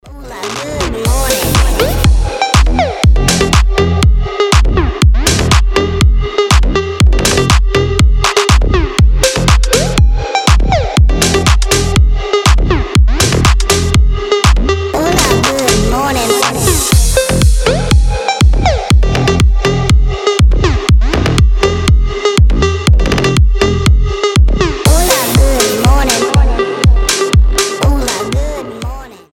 deep house
G-House